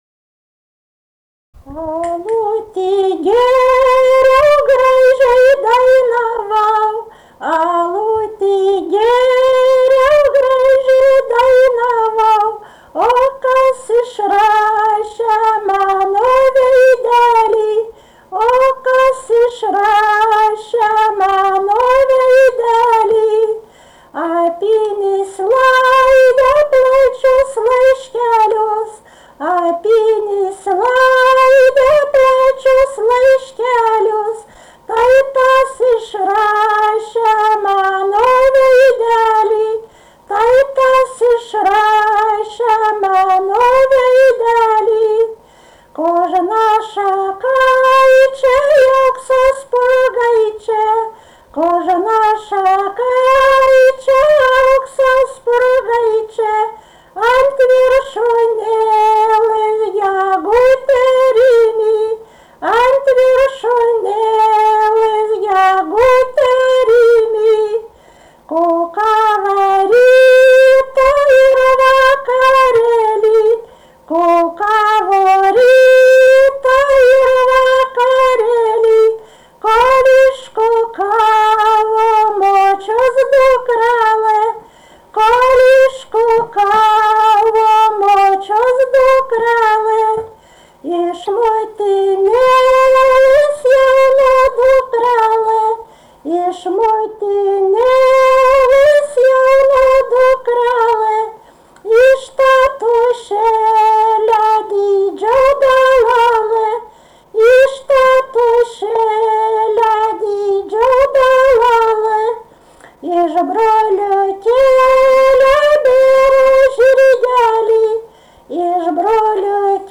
vaišių daina
vokalinis